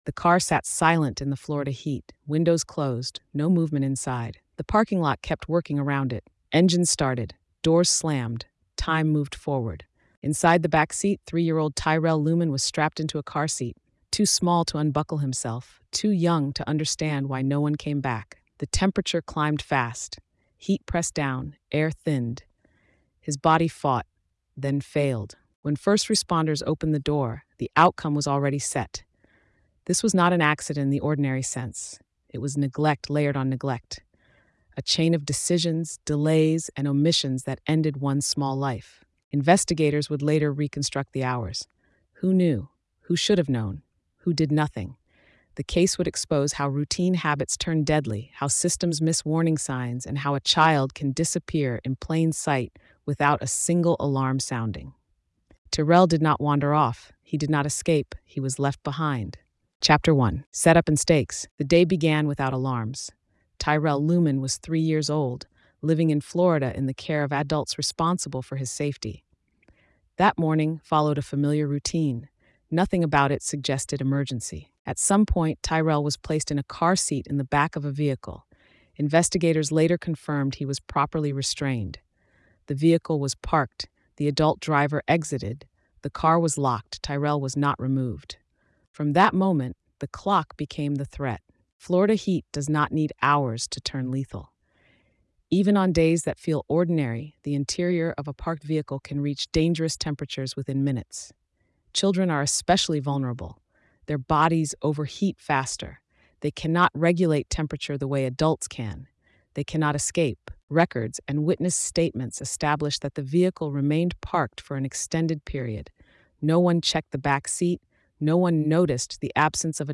Told in a neutral, gritty documentary tone, the story reconstructs the final hours, the investigative timeline, and the legal reasoning that transformed neglect into a homicide ruling. It focuses on responsibility, preventability, and the irreversible cost of routine failures in child care.